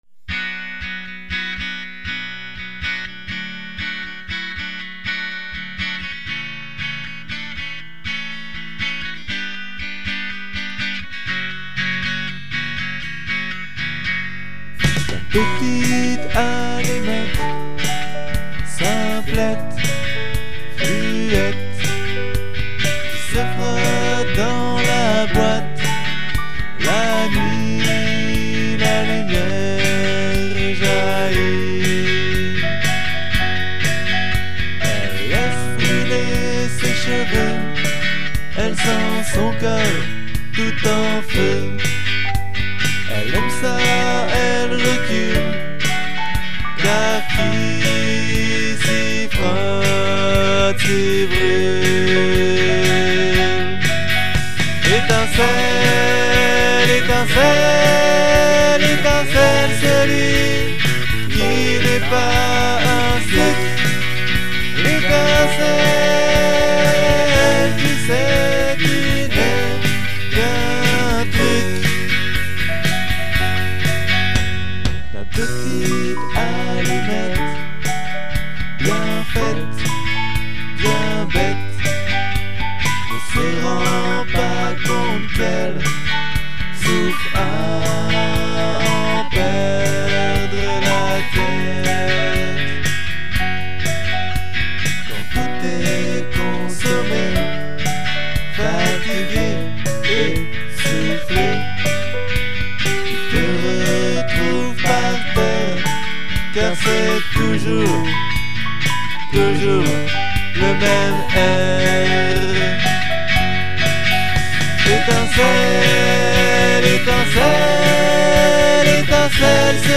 Et toujours le hit single de l'album studio: